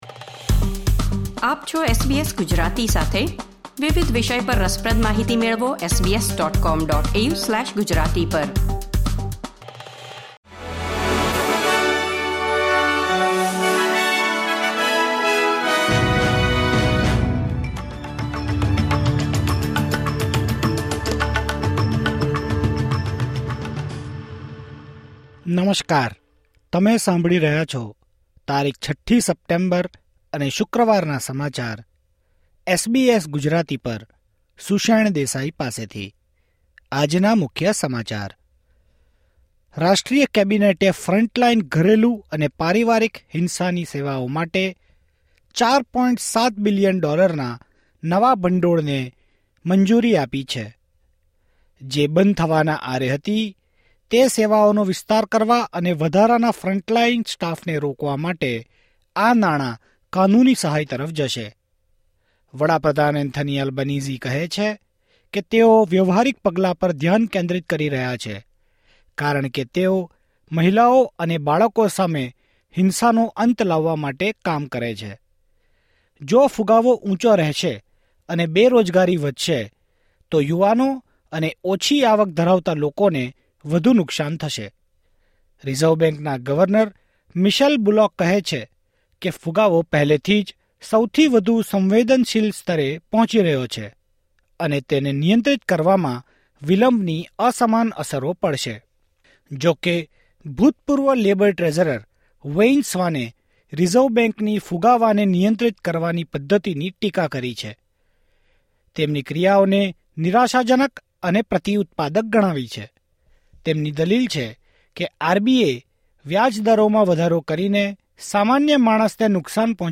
SBS Gujarati News Bulletin 6 September 2024